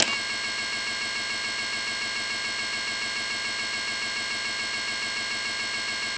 CcorZoomIn.wav